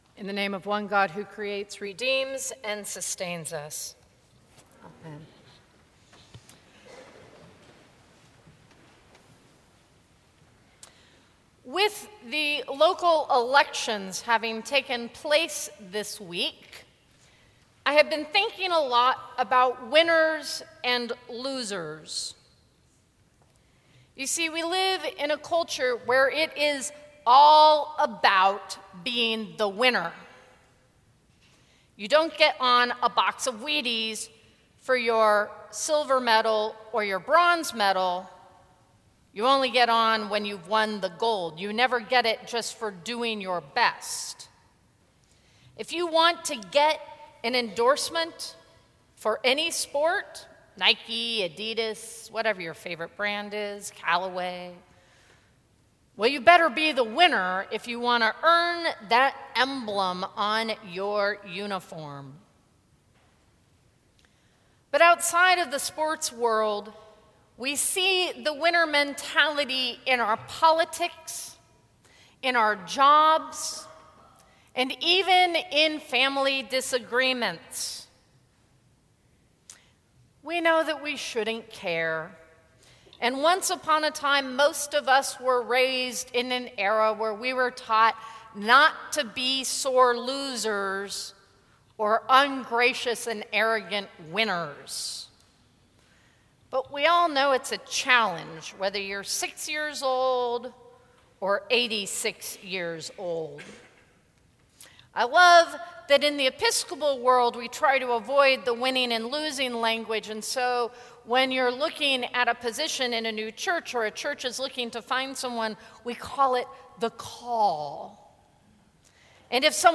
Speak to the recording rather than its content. Sermons from St. Cross Episcopal Church 11/10/2013 Dec 17 2013 | 00:12:41 1x 00:00 / 00:12:41 Subscribe Share Apple Podcasts Spotify Overcast RSS Feed Share Link Embed